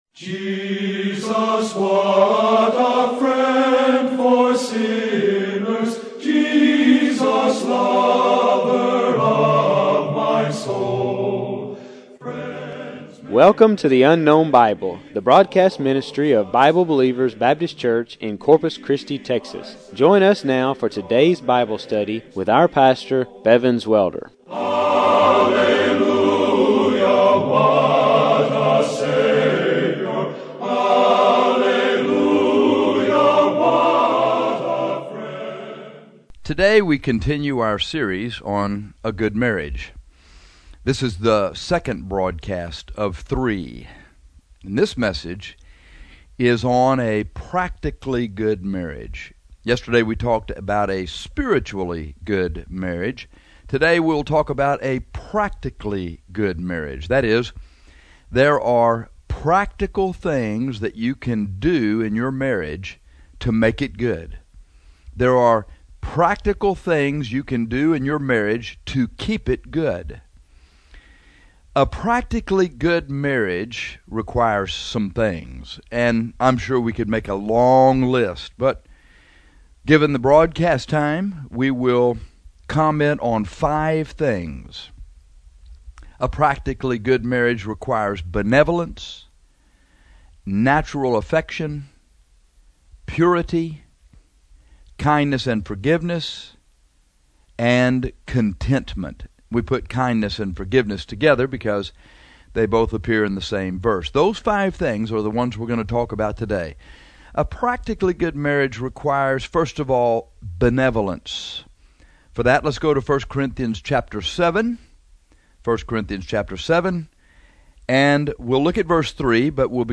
This is the second broadcast in our series on marriage.